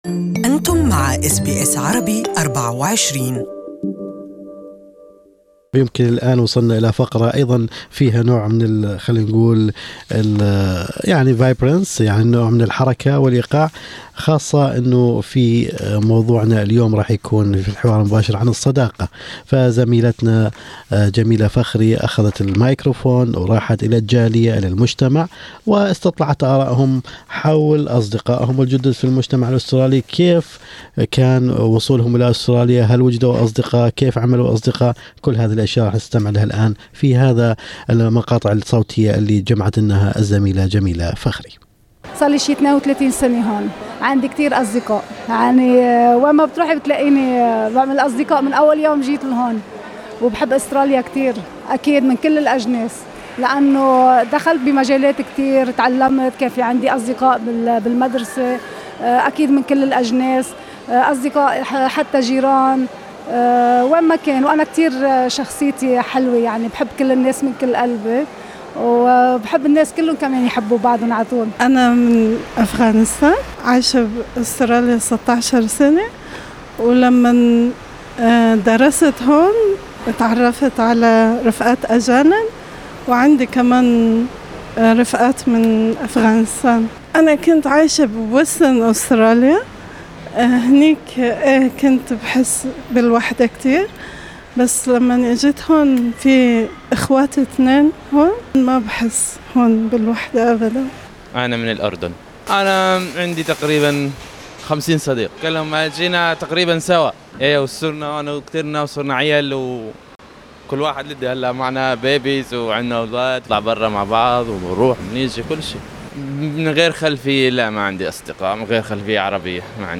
مجموعة من آراء ابناء الجالية العربية حول صداقاتهم في أستراليا
يعتبر الاندماج في المجتمع من أبرز التحديات التي يواجهها المهاجرون الى أستراليا وفيما تصعب اقامة صداقات جديدة على البعض يجد البعض الآخر الأمر بغاية السهولة. جال ميكروفون اس بي اس على عينة من أبناء الجالية العربية وسألهم عن اصدقائهم الجدد في المجتمع الأسترالي وعما اذا كان لديهم أصدقاء من خلفيات اثنية غير العربية شارك